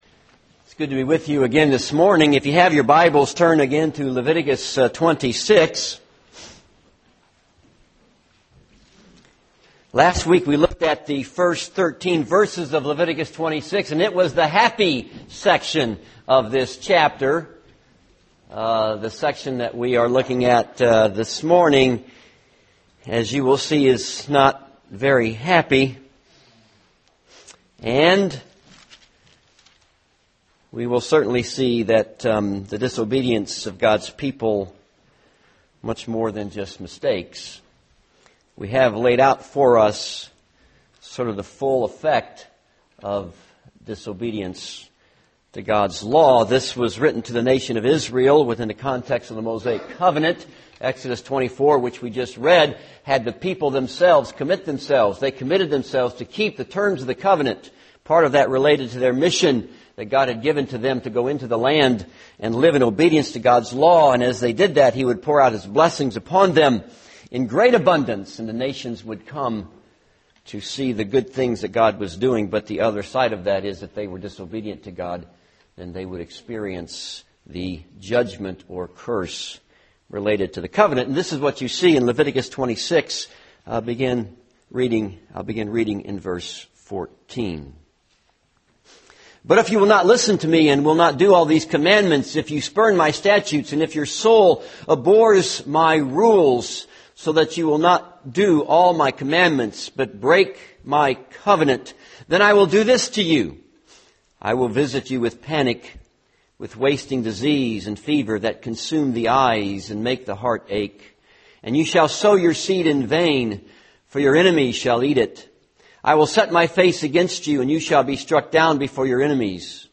This is a sermon on Leviticus 26:14-46.